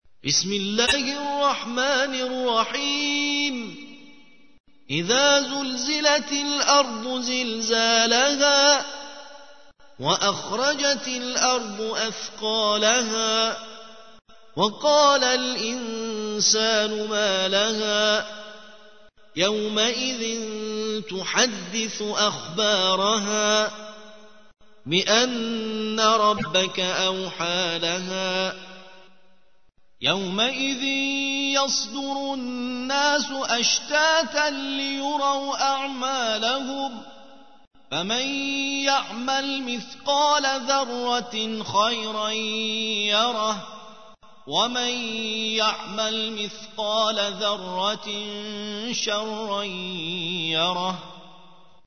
99. سورة الزلزلة / القارئ